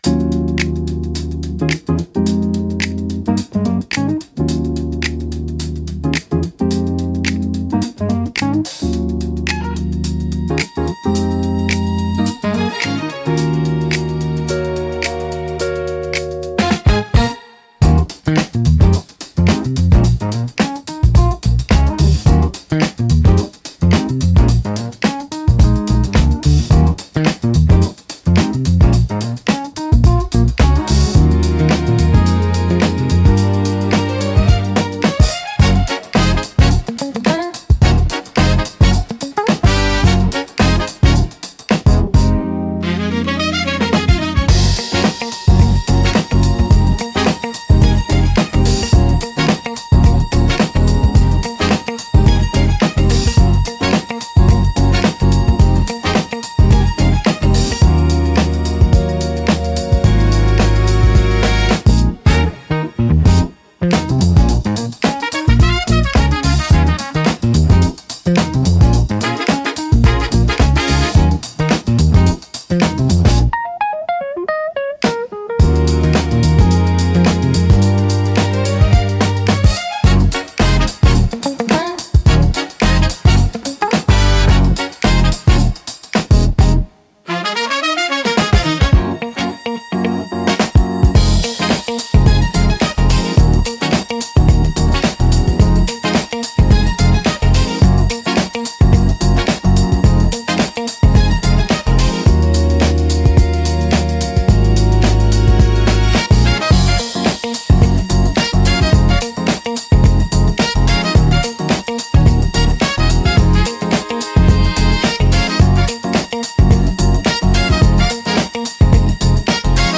落ち着きつつも躍動感があり、聴く人の気分を盛り上げつつリラックスさせる効果があります。